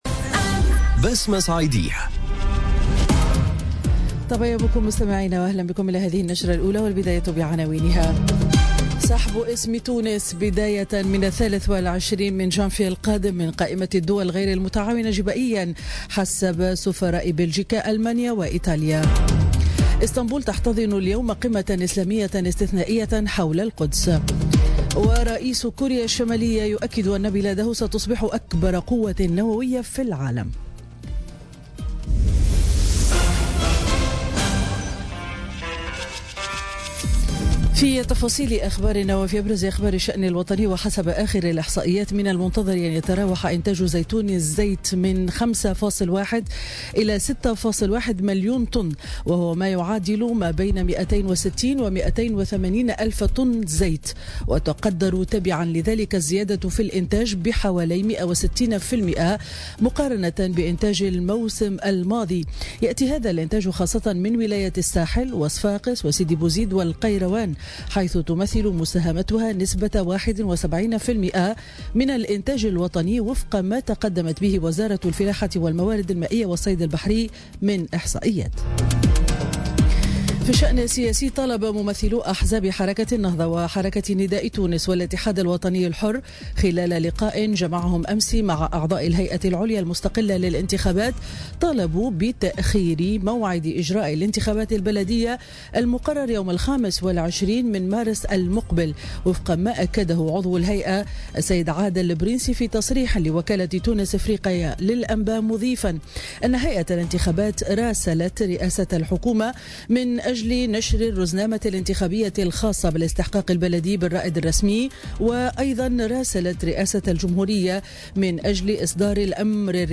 نشرة الأخبار السابعة صباحا ليوم الإربعاء 13 ديسمبر 2017